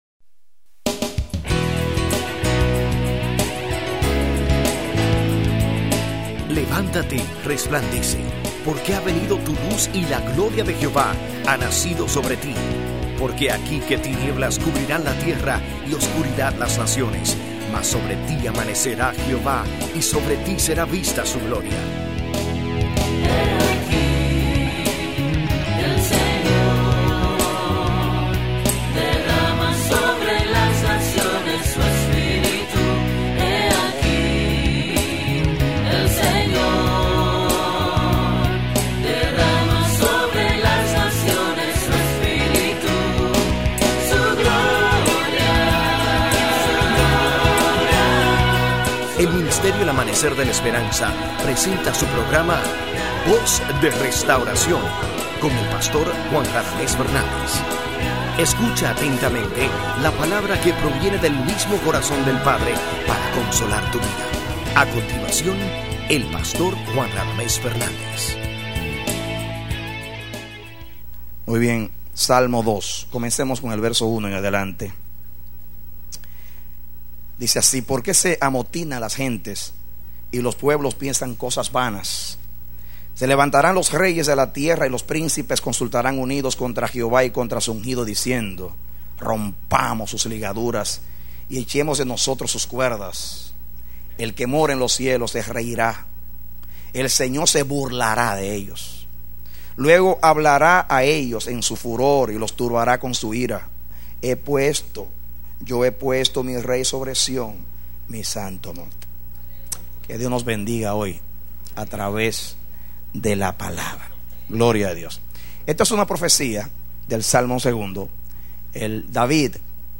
Predicado Marzo 18, 2001